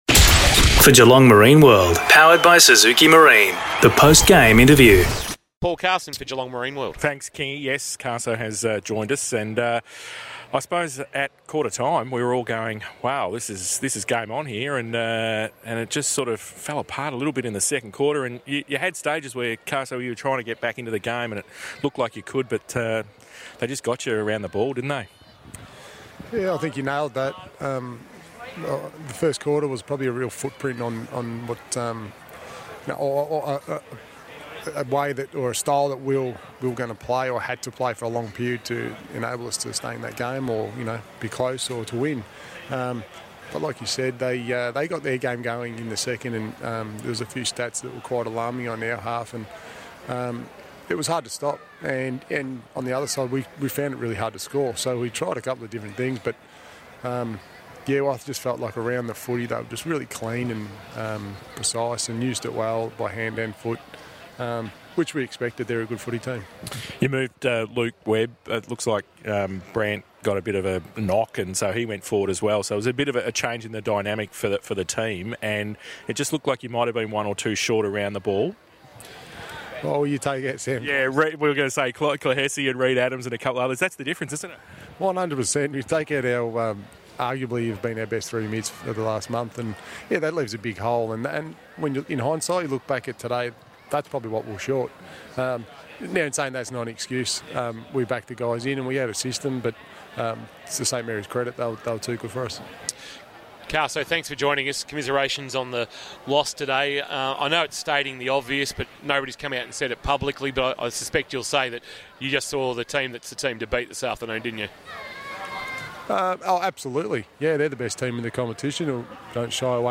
2022 - GFL ROUND 7 - ST MARYS vs. ST JOSEPHS: Post-match Interview